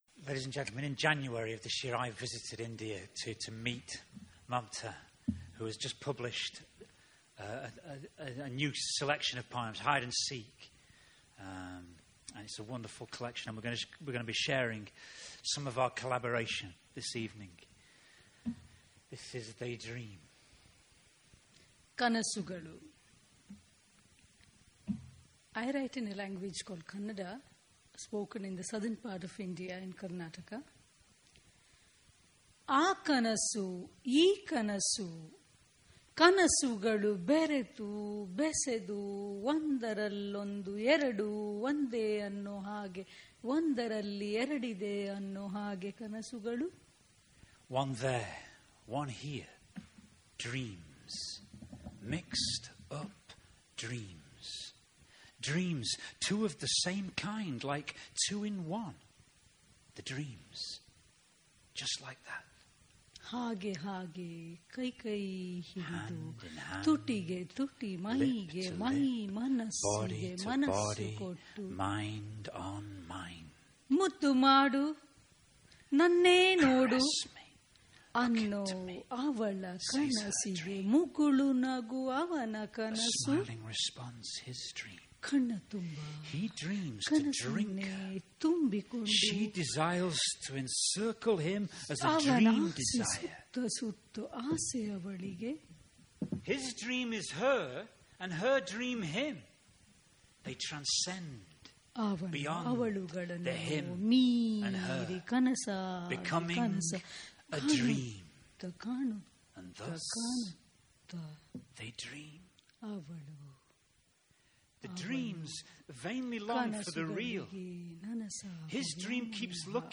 Ledbury Poetry Festival 2015